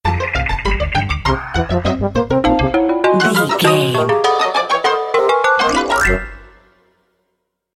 Short music, corporate logo or transition between images,
Epic / Action
Fast paced
In-crescendo
Uplifting
Ionian/Major
cheerful/happy
futuristic
industrial
groovy
funky
synthesiser